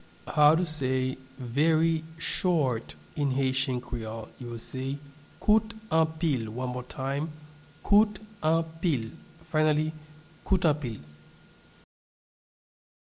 a native Haitian voice-over artist can be heard in the recording here
Very-short-in-Haitian-Creole-Kout-anpil-pronunciation.mp3